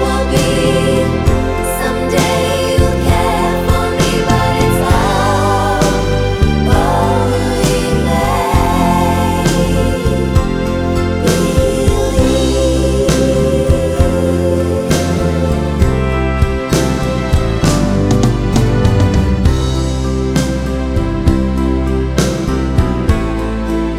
No Backing Vocals Crooners 3:02 Buy £1.50